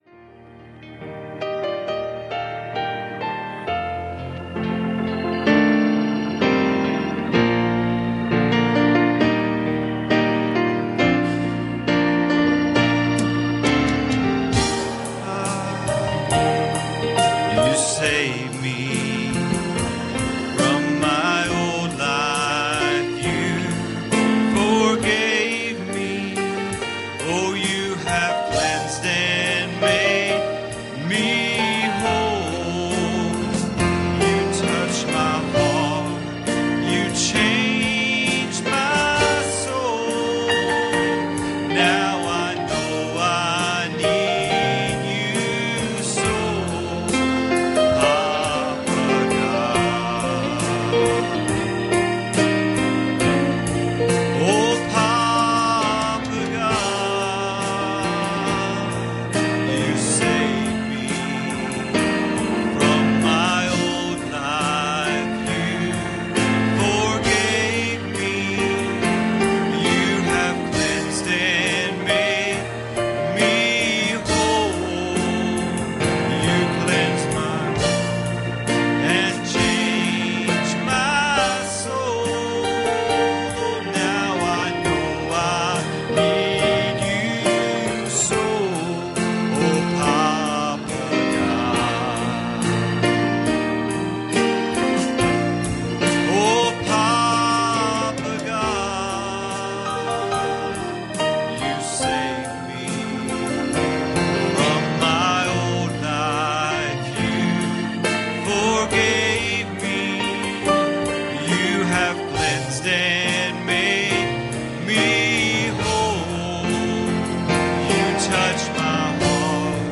Passage: Mark 5:25 Service Type: Wednesday Evening